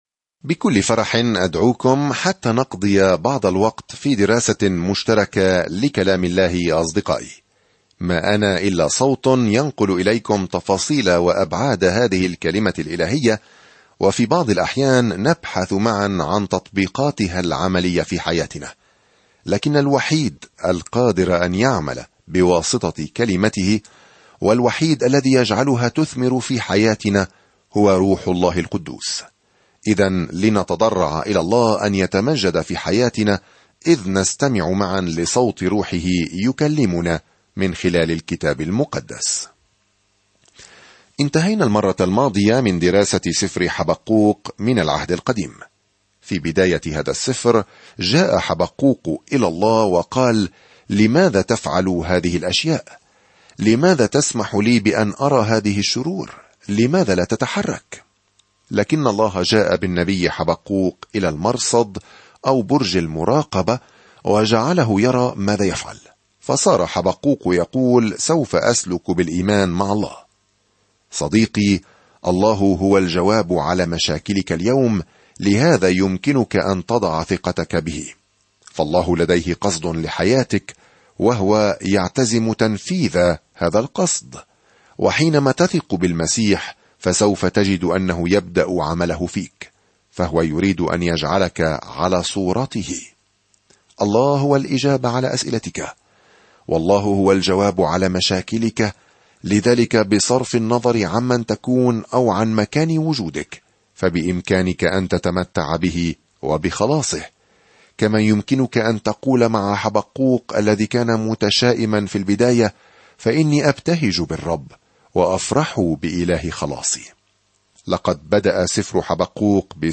الكلمة صَفَنْيَا 1 ابدأ هذه الخطة يوم 2 عن هذه الخطة يحذر صفنيا إسرائيل من أن الله سيدينهم، لكنه يخبرهم أيضًا كم يحبهم وكيف سيبتهج بهم يومًا ما بالغناء. سافر يوميًا عبر صفنيا وأنت تستمع إلى الدراسة الصوتية وتقرأ آيات مختارة من كلمة الله.